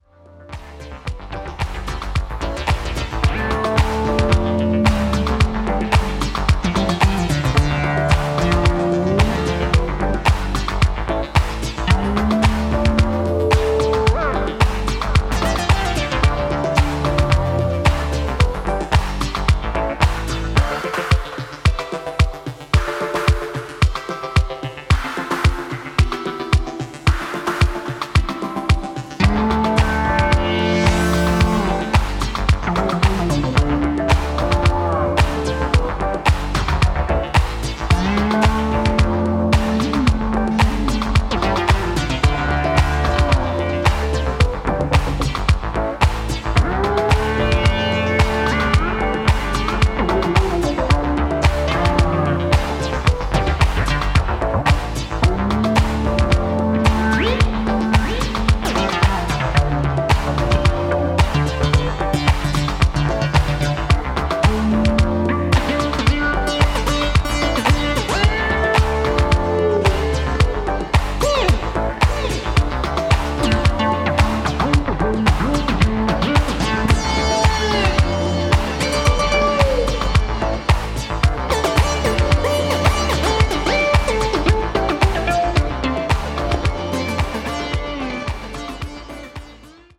disco
with spaced-out synth stabs
Disco